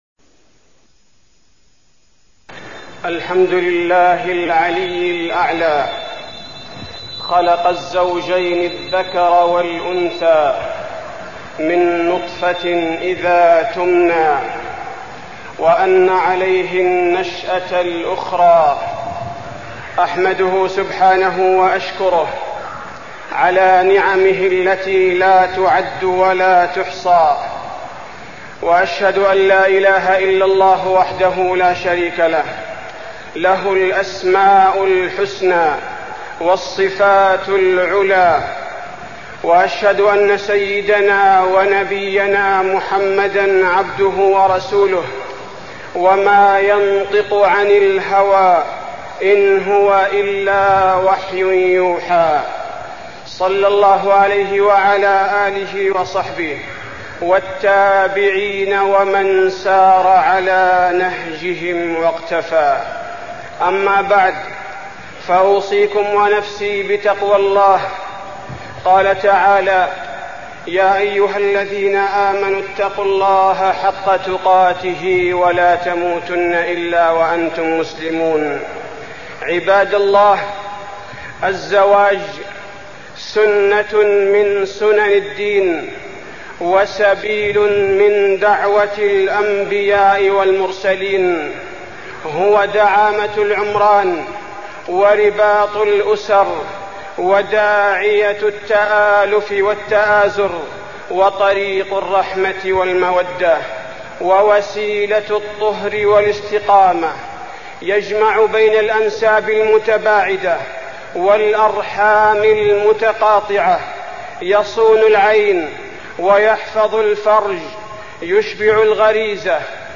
تاريخ النشر ٢٢ ربيع الأول ١٤٢٢ المكان: المسجد النبوي الشيخ: فضيلة الشيخ عبدالباري الثبيتي فضيلة الشيخ عبدالباري الثبيتي الزواج